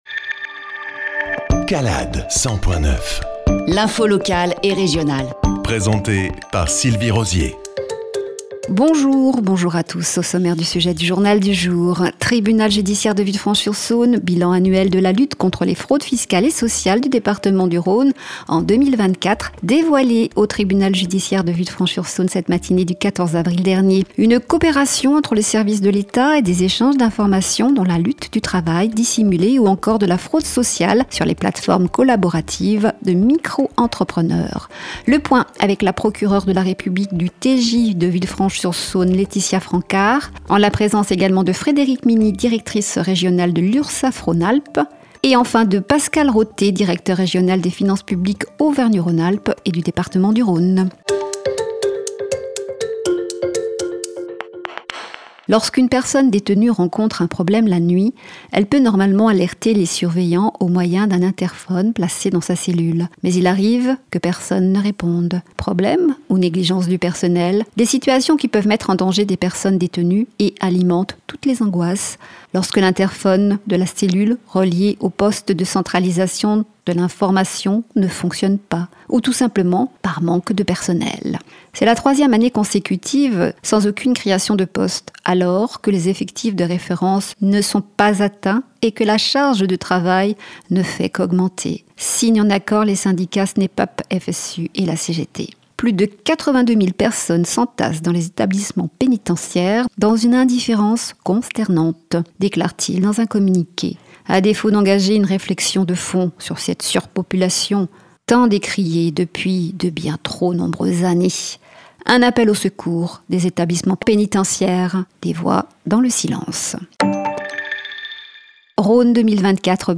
JOURNAL – 160425